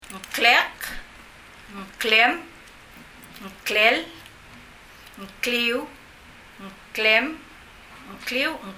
以下の発音例は、 ngklek, ngklem, ngklel,・・・などmy name, your name, his/her name・・・の例です。 k の音の一瞬前に入る、ng サウンドに注意して、聴いてみましょう。
発音
NGsound_3.mp3